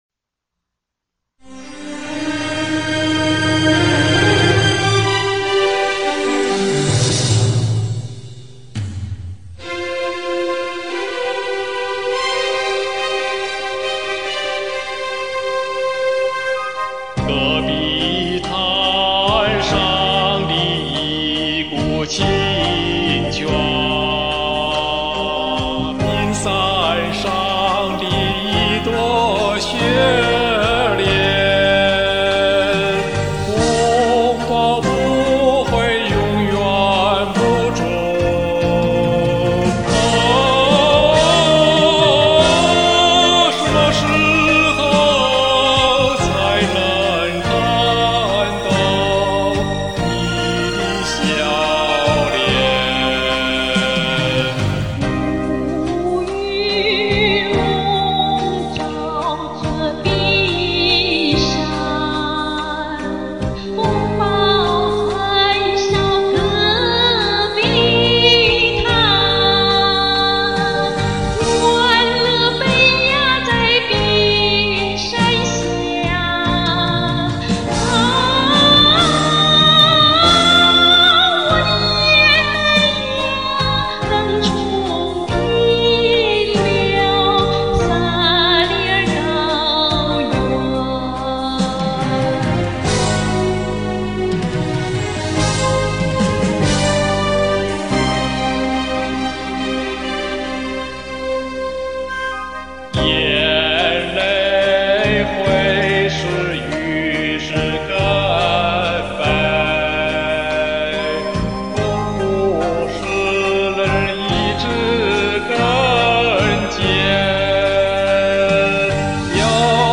这两首电影插曲的歌词内容简单，均带有新疆民族风味，旋律独特，曲调忧郁，可以说这种音乐美有些壮烈和哀怨。